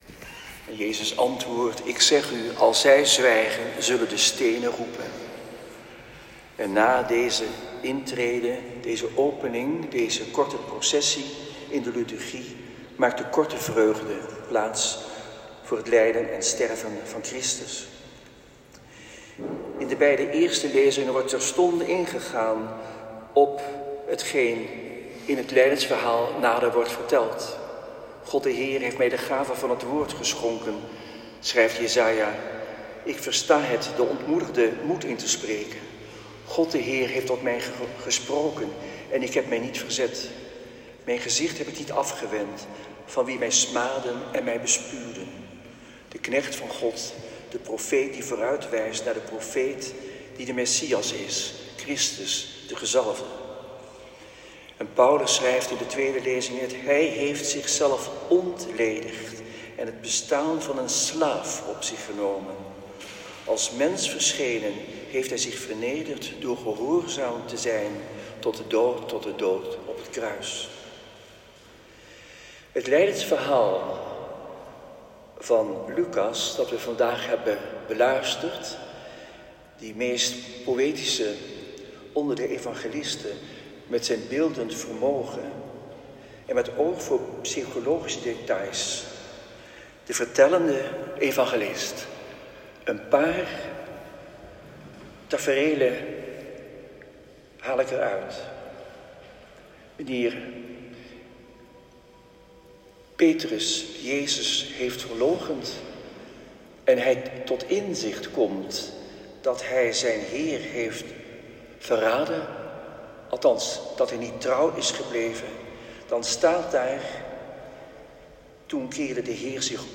Palmzondag.
Preek.m4a